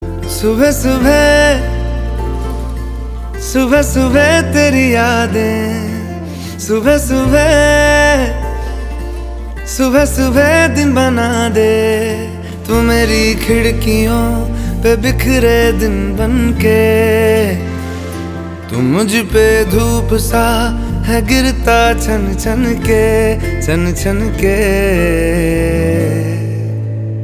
Categories: Bollywood Ringtones